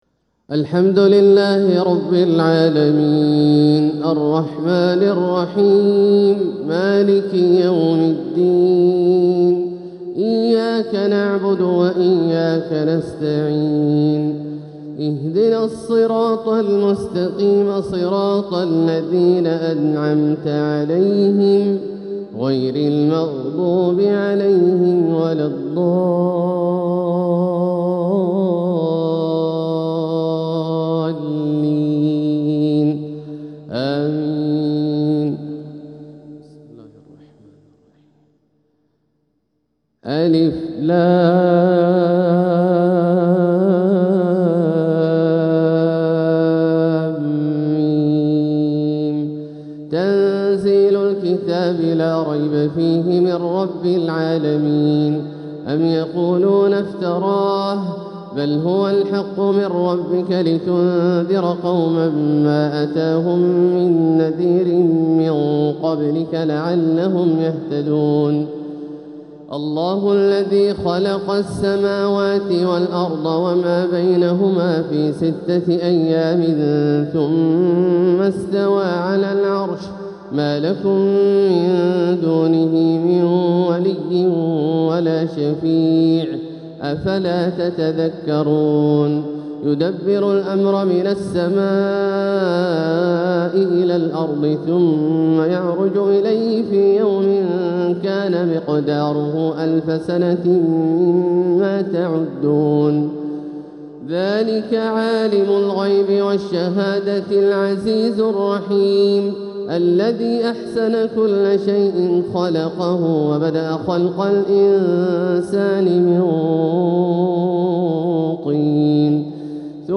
تلاوة لسورتي السجدة و الإنسان | فجر الجمعة 6-3-1447هـ > ١٤٤٧هـ > الفروض - تلاوات عبدالله الجهني